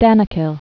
(dănə-kĭl, də-näkēl)